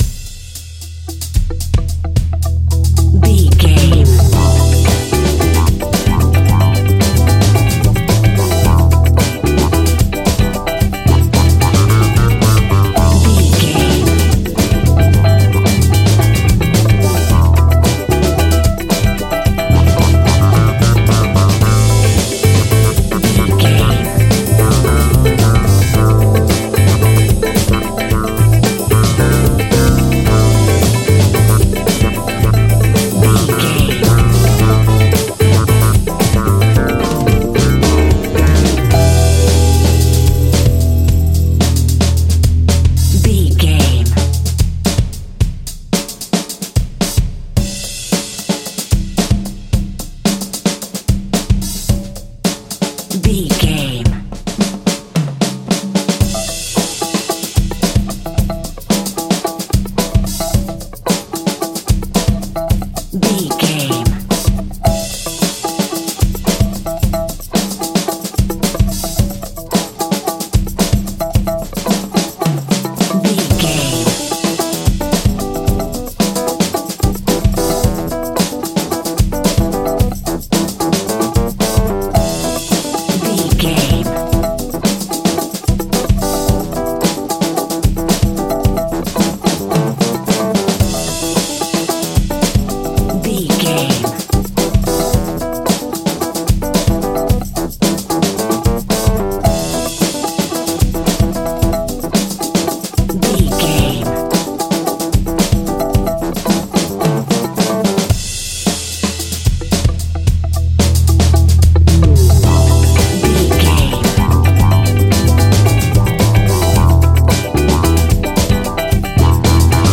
Aeolian/Minor
lively
electric guitar
electric organ
bass guitar
saxophone
percussion